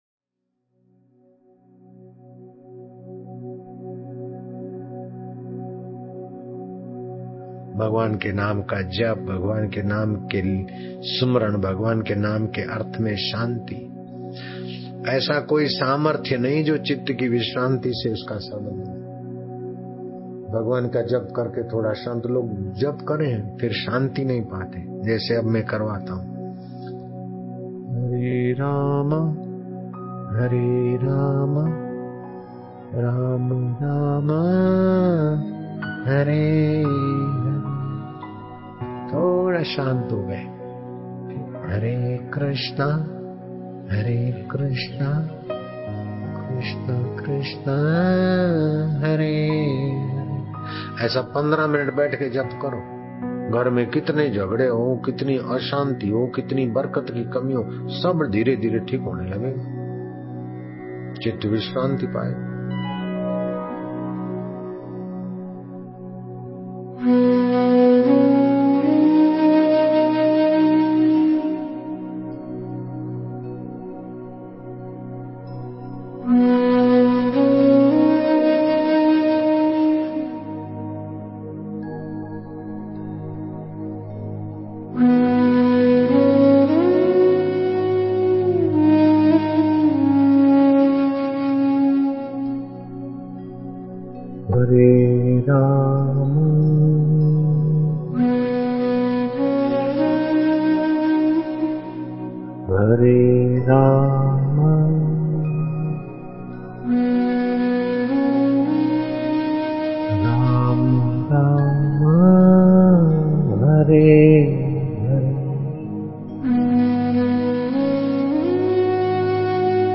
Oct 20,2025 Monday : Misc : HARE RAM HARE KRISHNA JAP ,BAPUJI NE KAHA DIWALI KE RAAT ME VISHESH JAP KA FAYADA Sandhya ,Bhajan